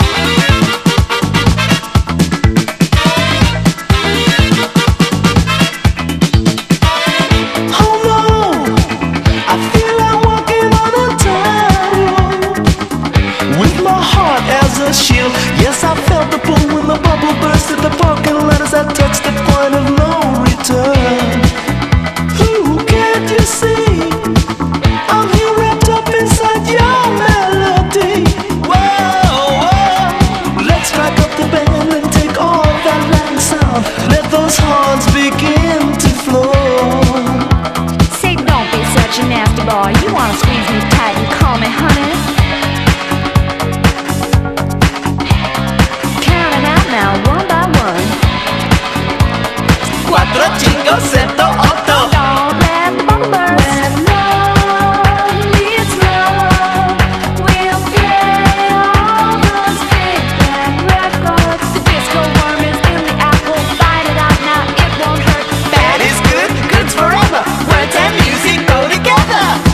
EASY LISTENING / EASY LISTENING / JAZZ / FLUTE
ヴァイブがメロウにたゆたう